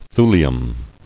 [thu·li·um]